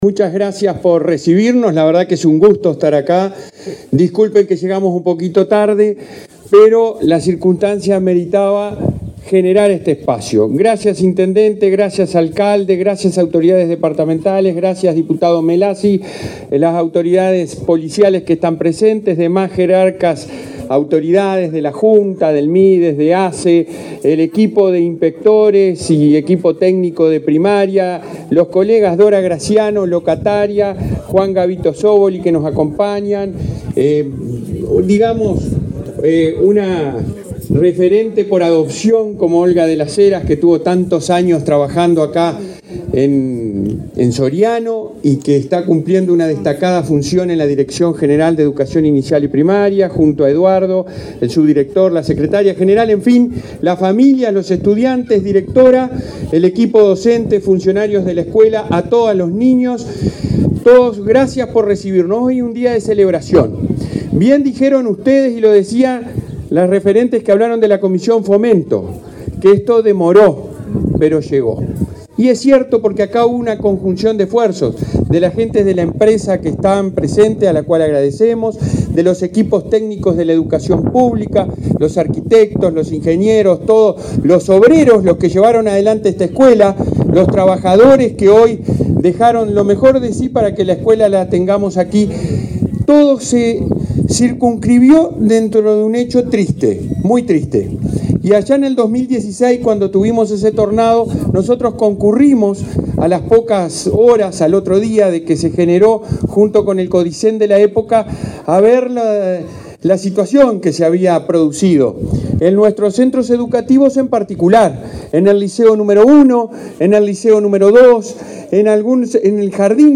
Palabras del presidente de la ANEP, Robert Silva
Palabras del presidente de la ANEP, Robert Silva 19/10/2023 Compartir Facebook X Copiar enlace WhatsApp LinkedIn El presidente de la Administración Nacional de Educación Pública (ANEP), Robert Silva, participó, este 19 de octubre en Soriano, en la inauguración del edificio de la escuela rural n.º 64 de Dolores.